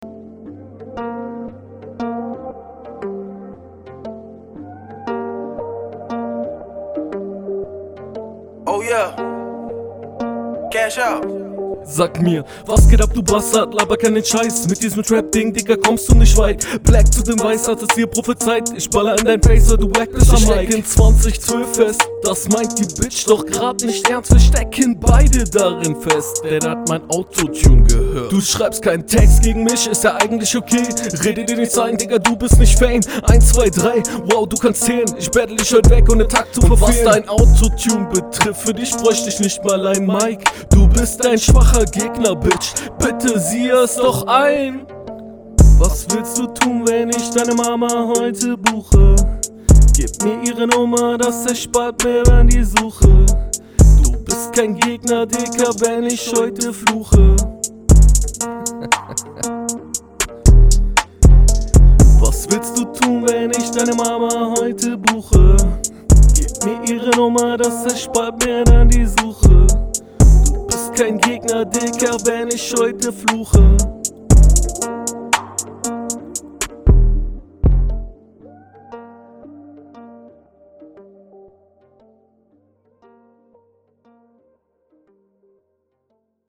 Flow: Finde den Stimmeinsatz noch unausgereift an einigen Stellen und es wirkt teilweise noch unsicher …